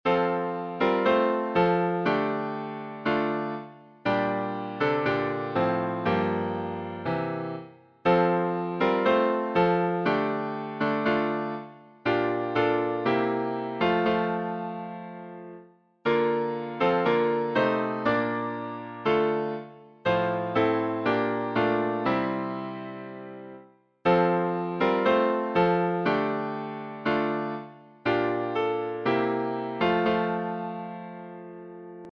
Hymns of praise